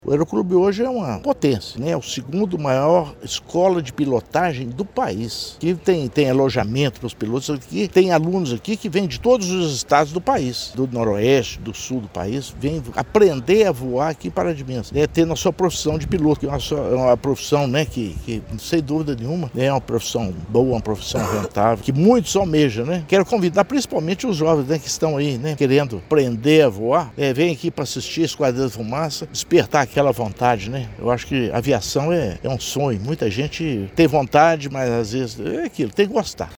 O prefeito Inácio Franco destacou que a cidade é uma referência no setor, abrigando a segunda maior escola de aviação do país e atraindo alunos até do exterior, como do Peru. Para o prefeito, o show da Esquadrilha da Fumaça, é uma oportunidade de despertar o sonho da aviação nos jovens: